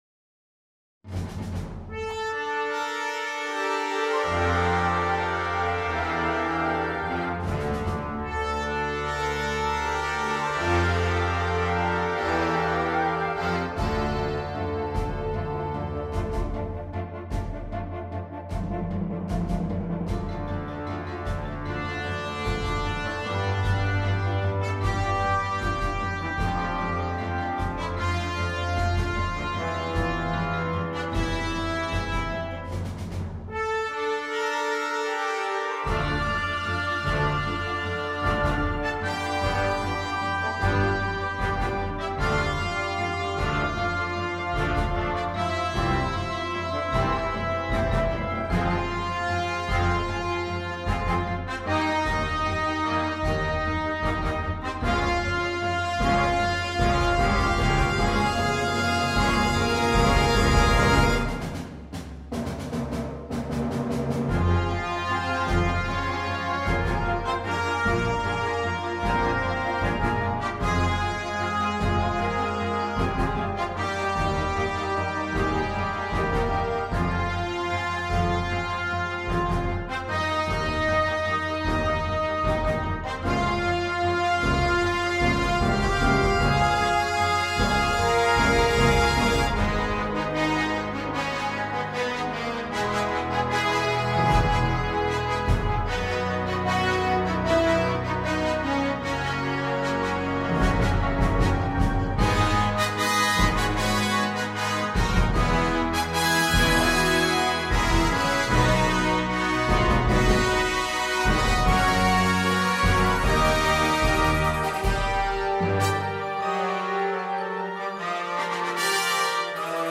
Gattung: Konzertwerk
Blasorchester
Die Atmosphäre ist Dunkel.